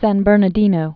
(săn bûrnə-dēnō, -nər-)